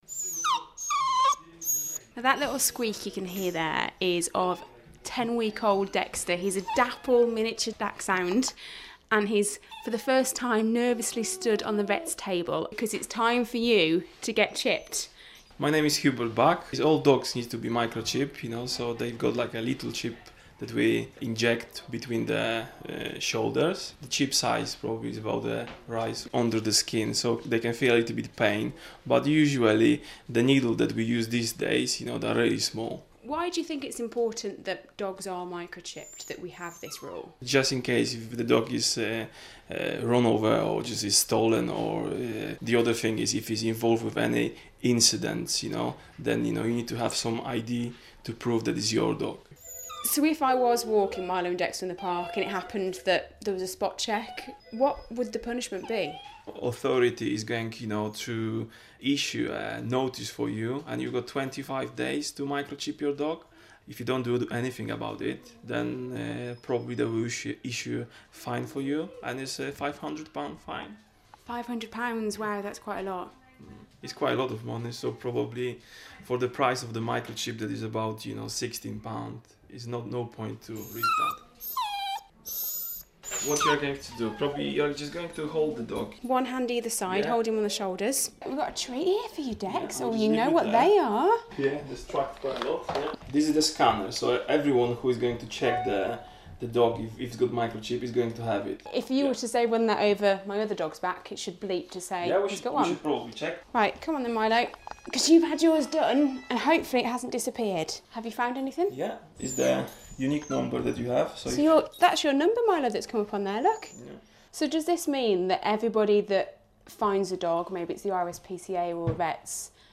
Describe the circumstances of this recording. (Broadcast on BBC Radio Stoke, April 2016)